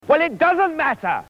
Category: Movies   Right: Personal
Tags: King Arthur Monty Python sounds Holy Grail audio clips Graham Chapman Funny Movie quotes